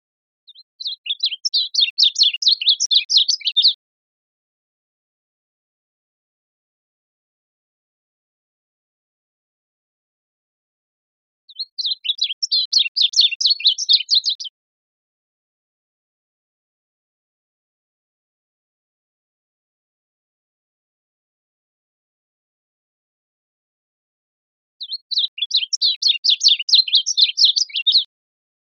Varied Bunting | Ask A Biologist
Bird Sound Type: Twittering Sex of Bird: Male